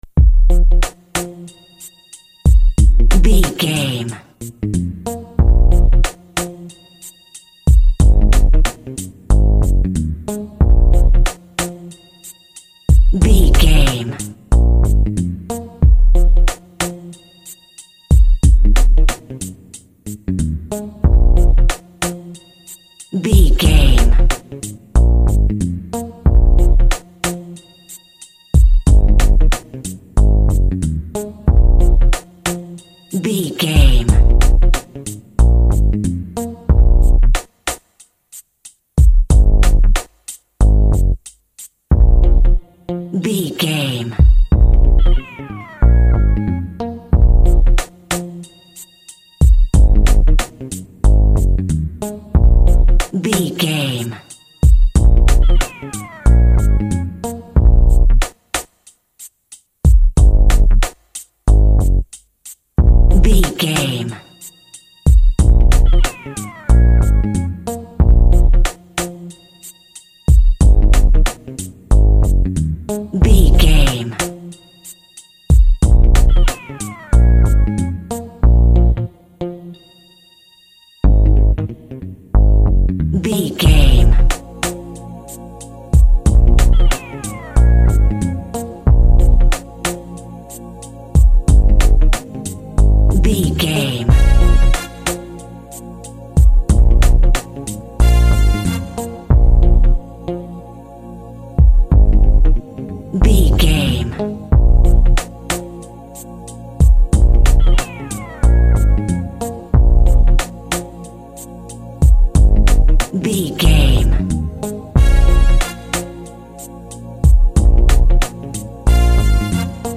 Rap Hip Hop Chill Out.
Aeolian/Minor
B♭
synth lead
synth bass
hip hop synths